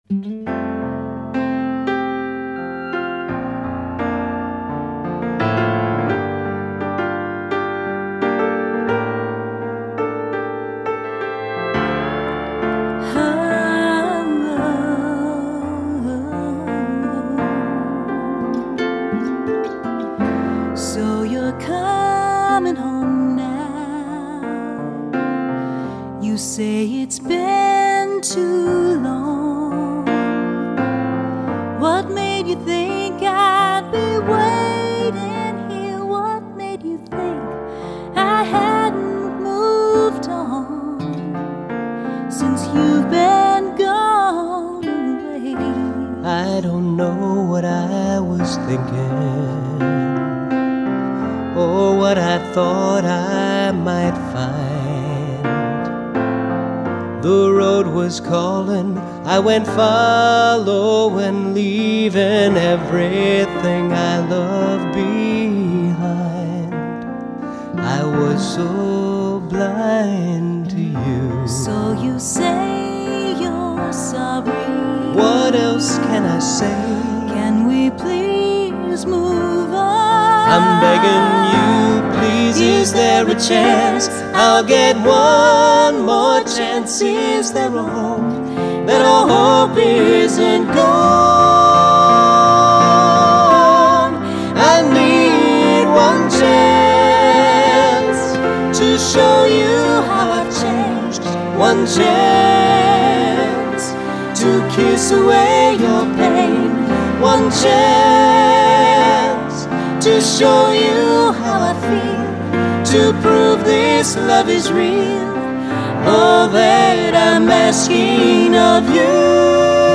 Ballad Duet